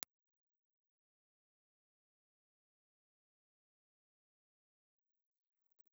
Dynamic
Cardioid
Impulse Response file of the Oktava MD-44 microphone from 1960.
Oktava_MD44_IR.wav